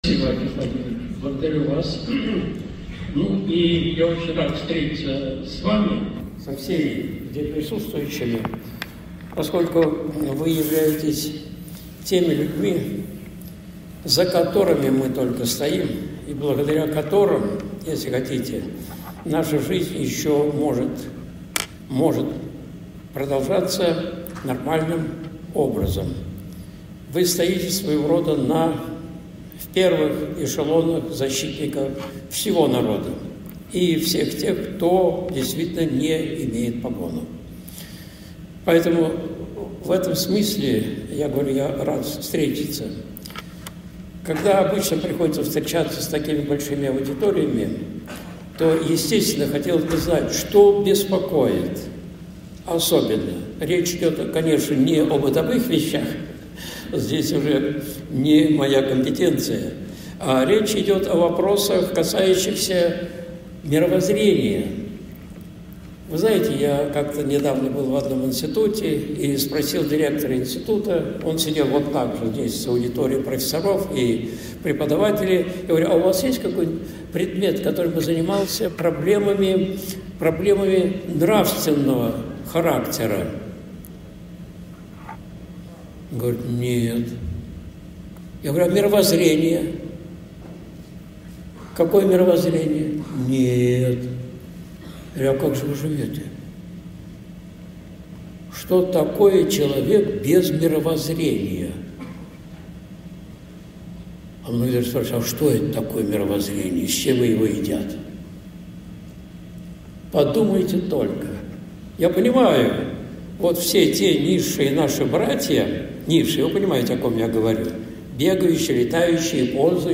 Вопрос, который никогда нельзя забывать в своей жизни (Выступление в Росгвардии, 09.12.2024)
Видеолекции протоиерея Алексея Осипова